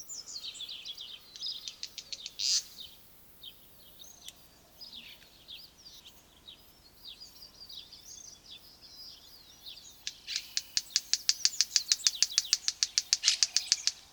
Wren-like Rushbird (Phleocryptes melanops)
Life Stage: Adult
Location or protected area: Necochea
Condition: Wild
Certainty: Photographed, Recorded vocal